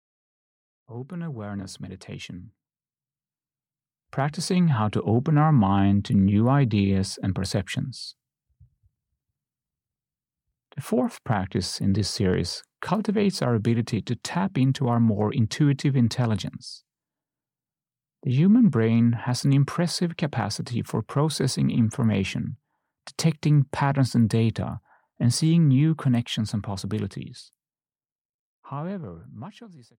Ukázka z knihy
This guided meditation delves into open awareness meditation, and how to open your mind to new ideas and impressions.